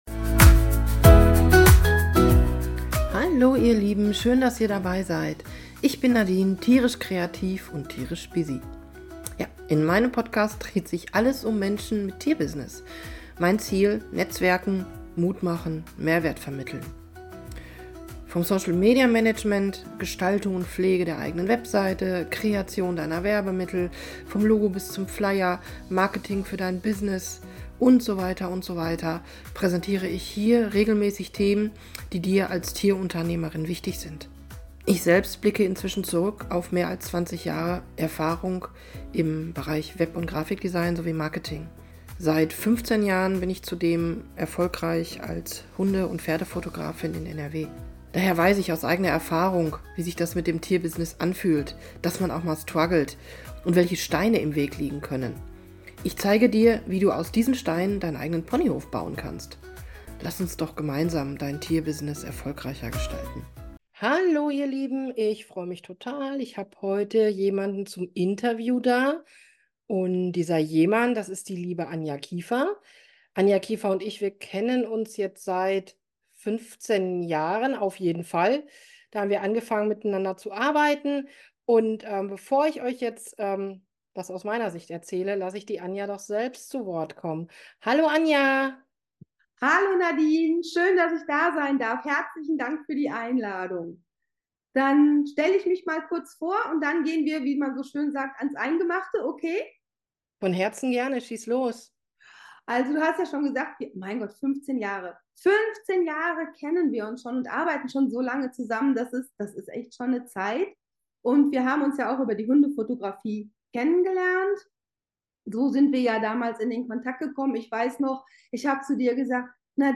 Eine Folge zum Schmunzeln - ein nicht ganz ernst zu nehmender Austausch